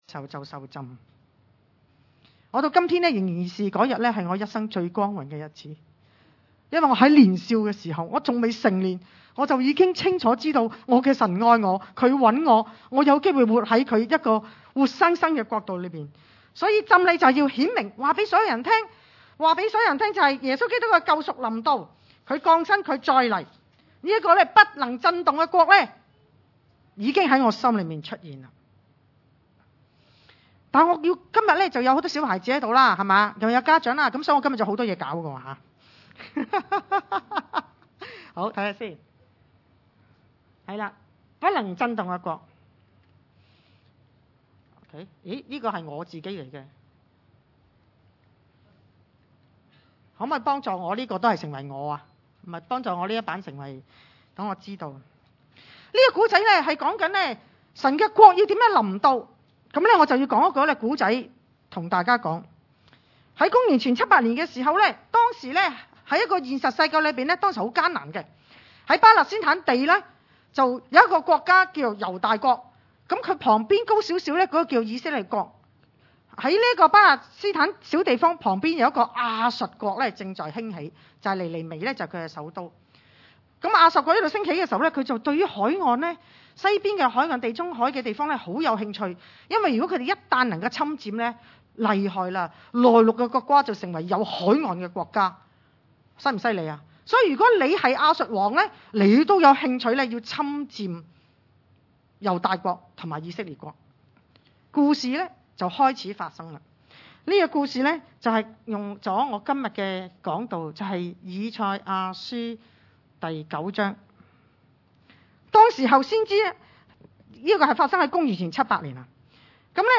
希伯來書12：26-28 崇拜類別: 特別聚會 以賽亞書9：1-7 1 但那受過痛苦的，必不再見幽暗。